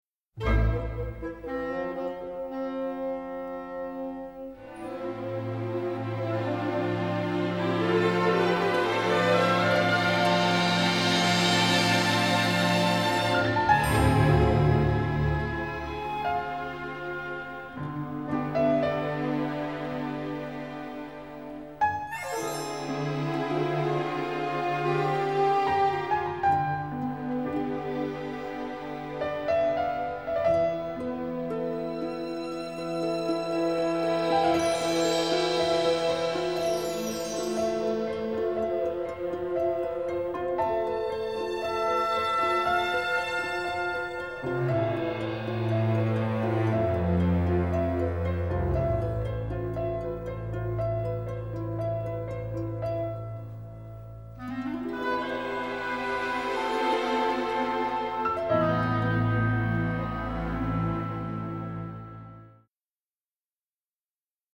Recorded in Germany.